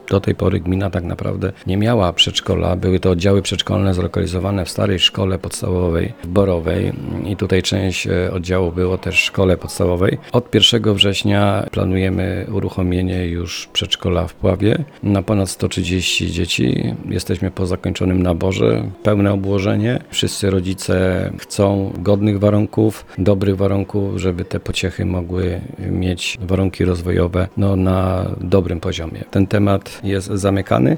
Mówi wójt gminy Borowa Stanisław Mieszkowski.